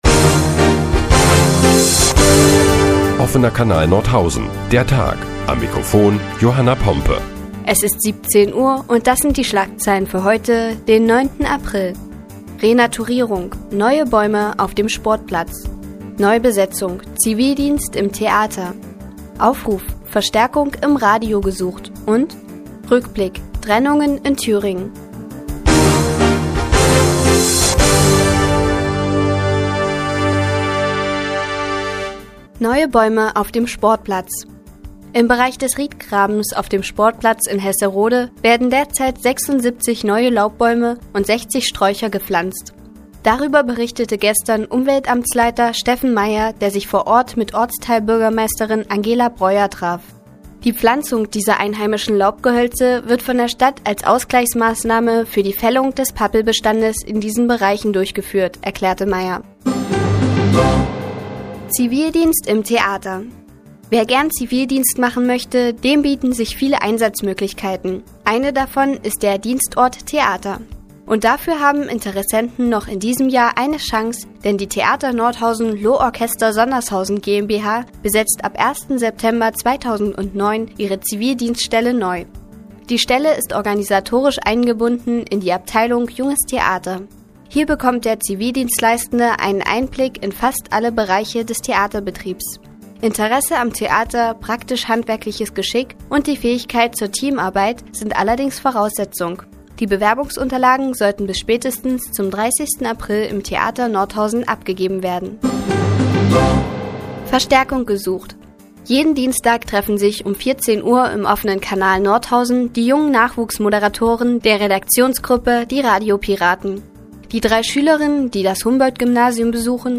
Die tägliche Nachrichtensendung des OKN ist nun auch in der nnz zu hören. Heute geht es unter anderem um neue Bäume auf dem Sportplatz und Zivildienst im Theater.